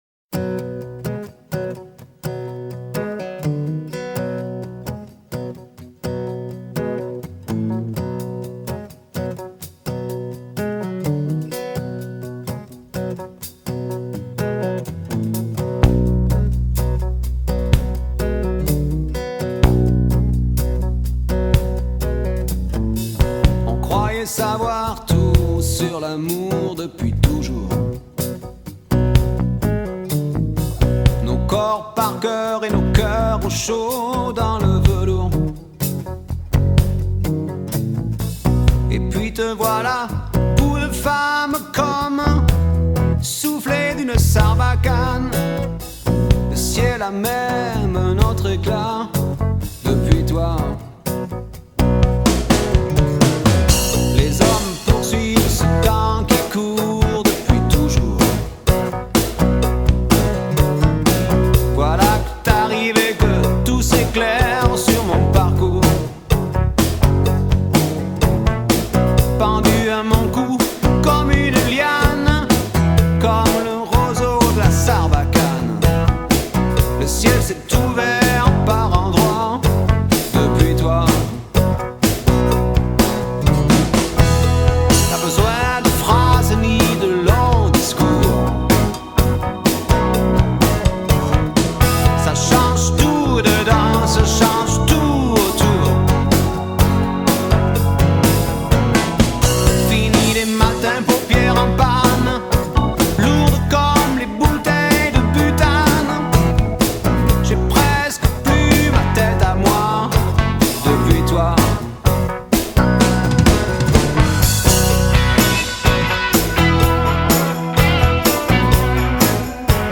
Sarbacane Drums
Back to Drums parts